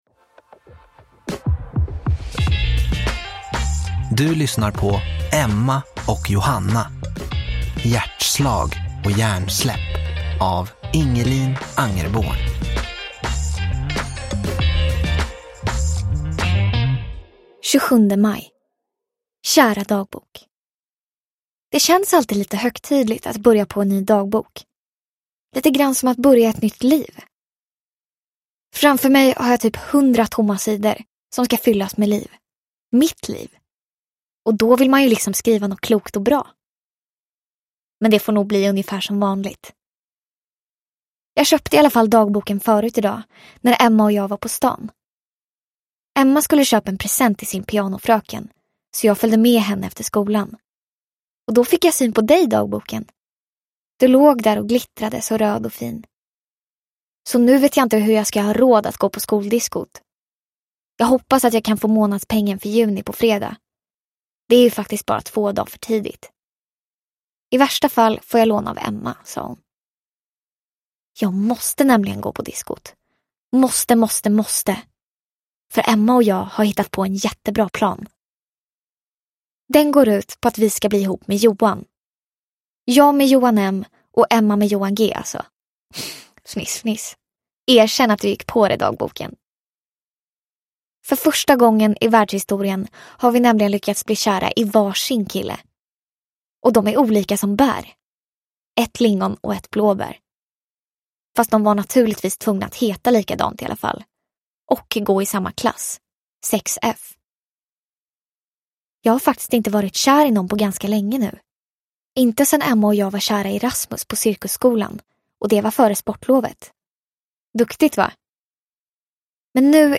Hjärtslag och hjärnsläpp – Ljudbok – Laddas ner